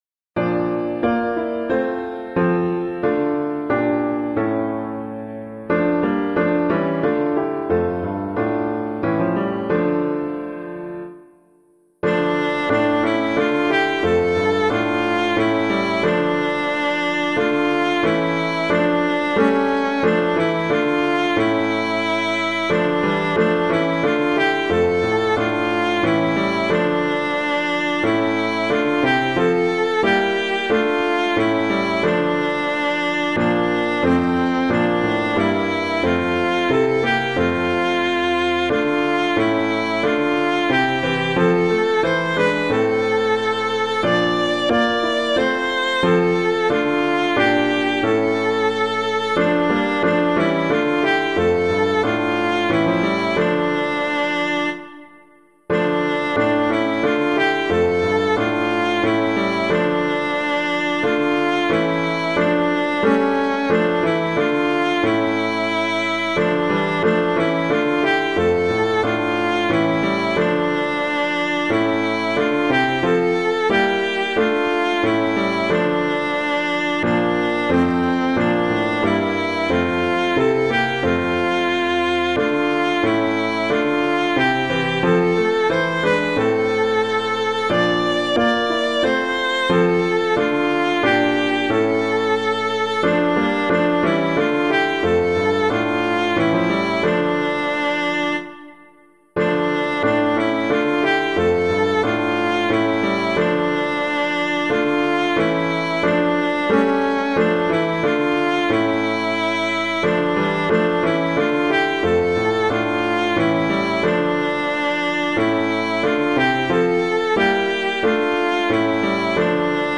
Hymn suitable for Catholic liturgy.